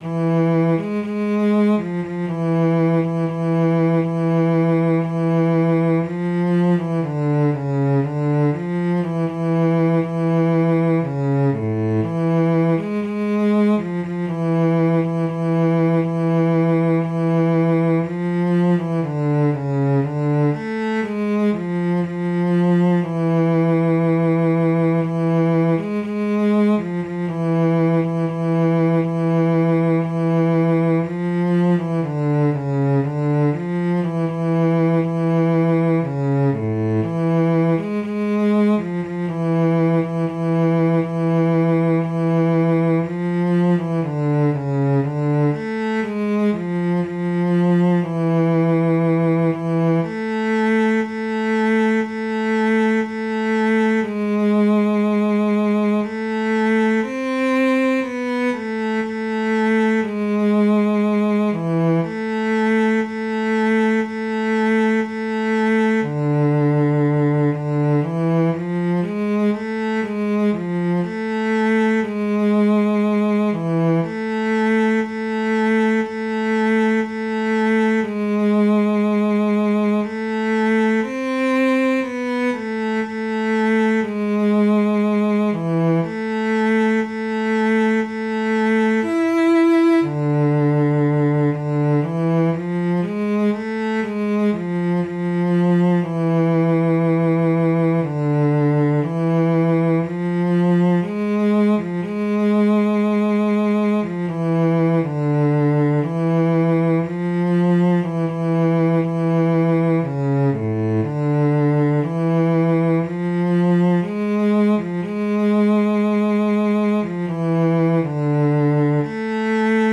Jewish Folk Song (Chabad-Lubavitch melody)
A minor ♩= 60 bpm